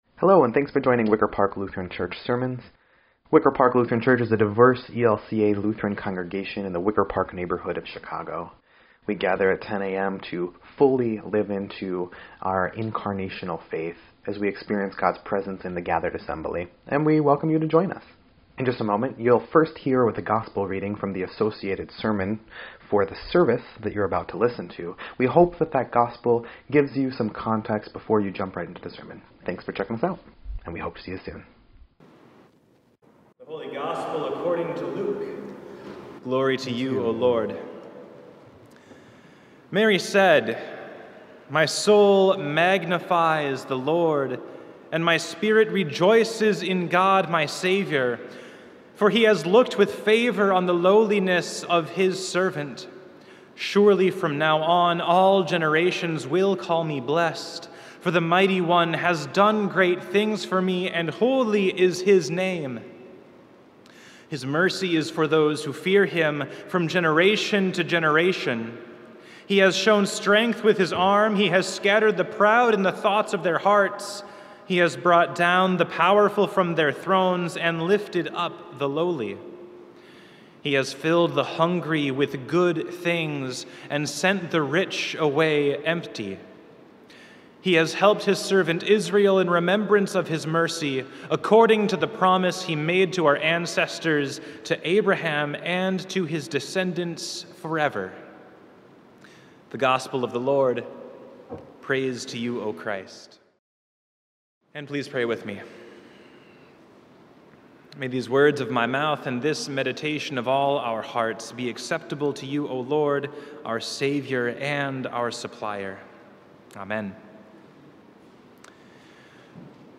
8.14.22-Sermon_EDIT.mp3